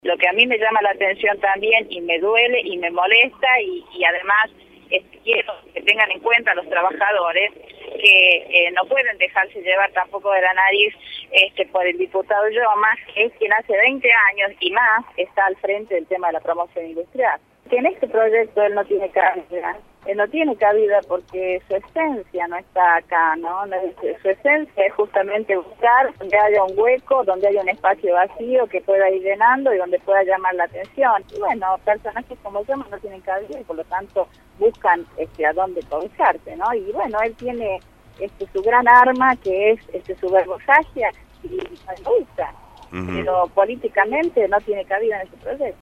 Teresita Luna, senadora nacional, por Radio La Red